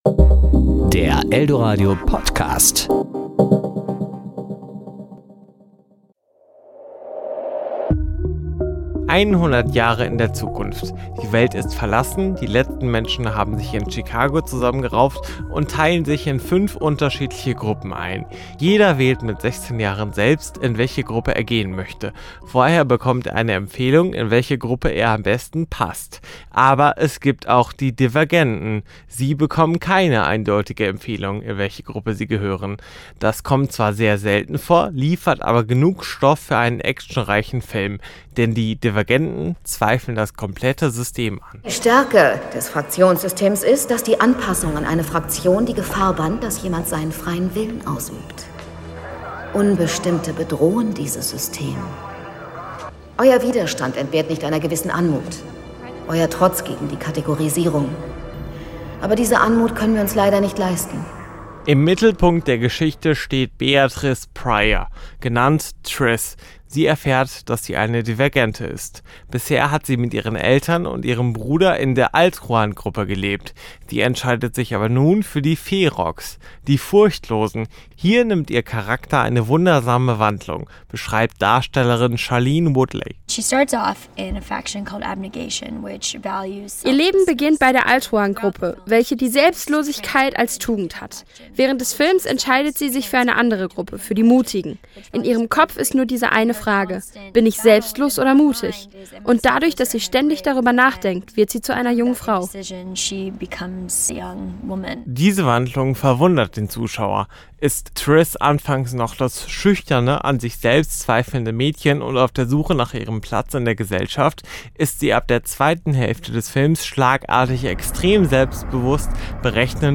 Kino-Rezension: Divergent